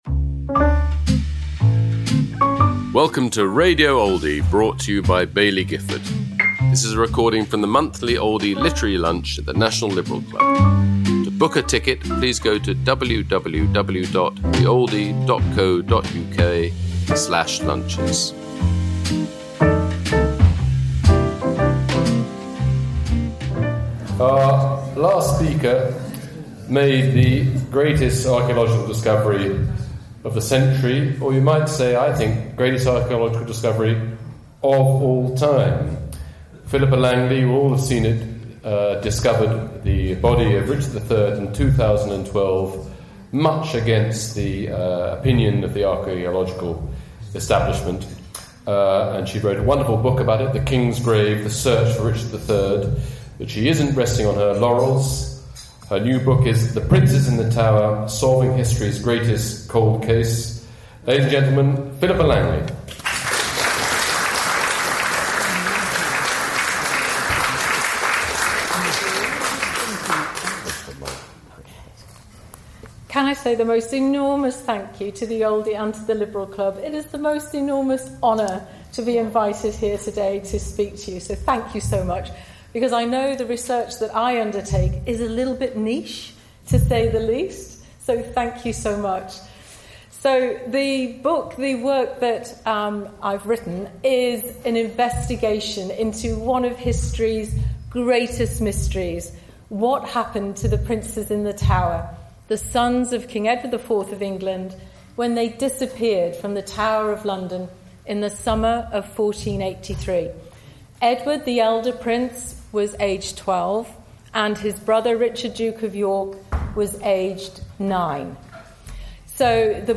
Philippa Langley speaking about her new book, The Princes in the Tower: Solving History's Greatest Cold Case, at the Oldie Literary Lunch, held at London’s National Liberal Club, on July 15th 2025.